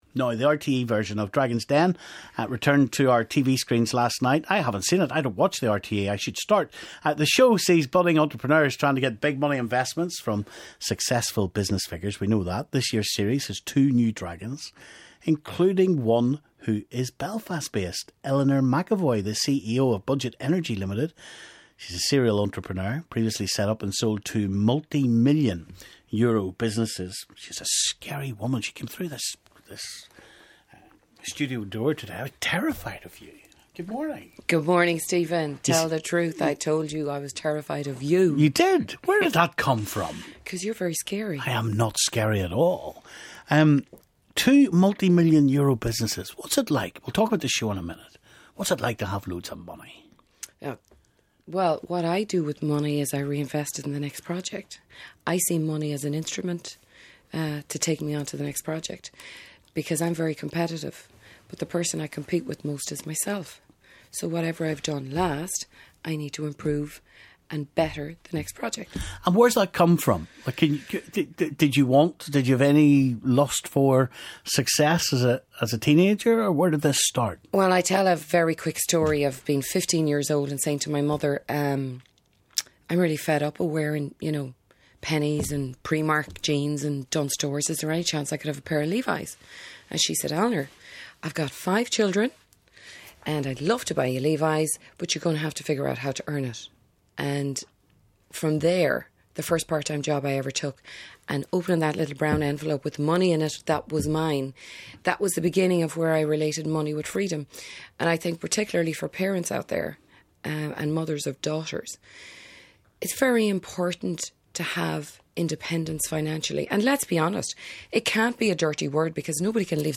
She joined Stephen in studio this morning